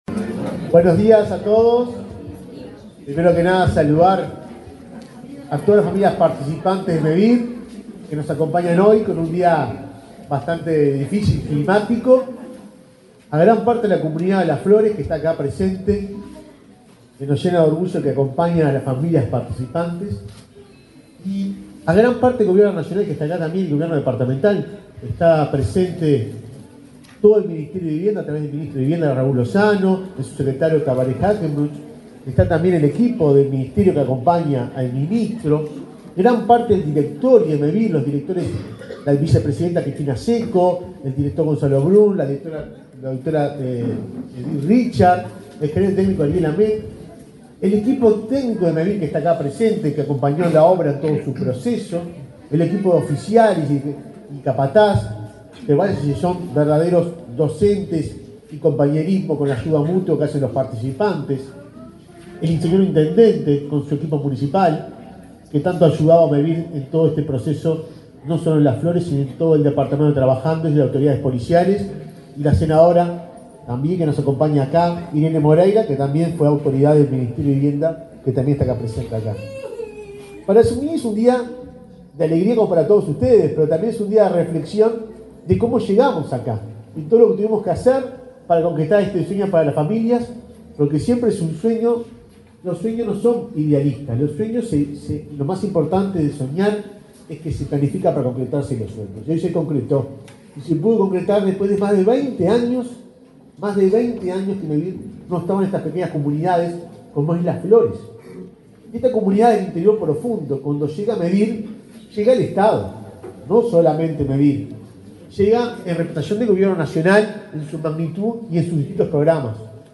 Palabras de autoridades en acto de Mevir en Rivera
El presidente de Mevir, Juan Pablo Delgado, y el ministro de Vivienda, Raúl Lozano, participaron, este jueves 22, de la inauguración de viviendas